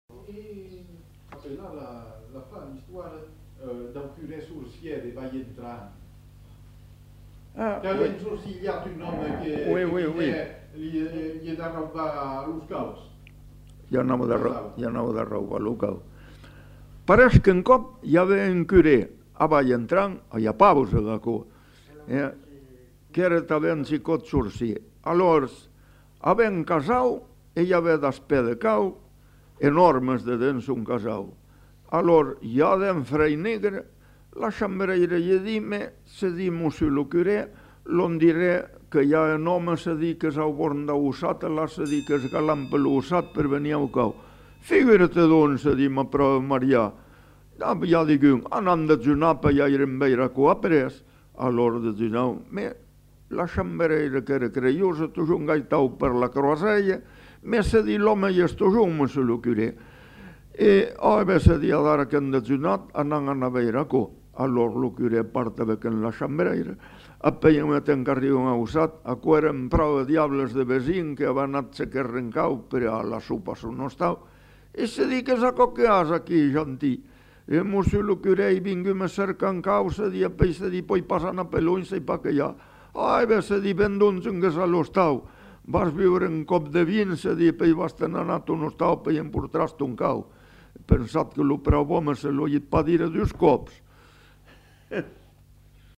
Aire culturelle : Médoc
Lieu : Saint-Yzans-de-Médoc
Genre : conte-légende-récit
Effectif : 1
Type de voix : voix d'homme
Production du son : parlé